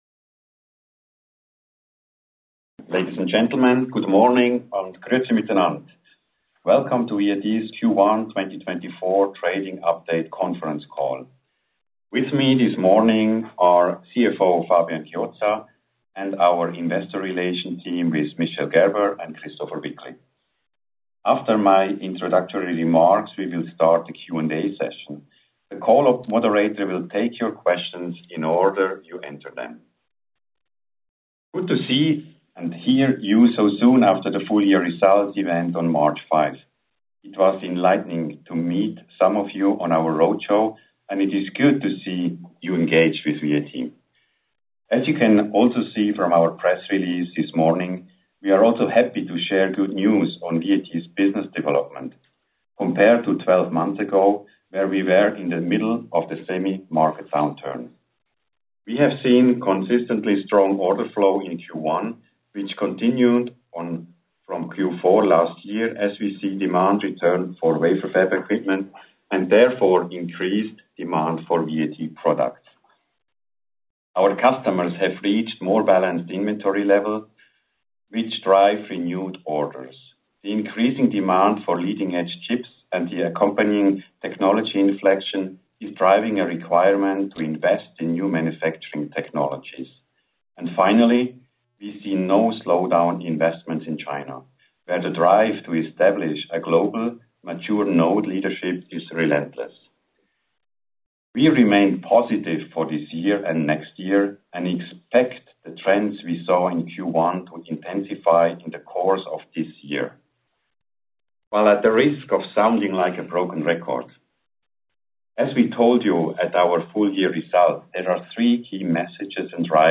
Conference Call - VAT Q1 2021 Trading Update
Conference_Call_VAT_Q1_2024_Trading_Update.mp3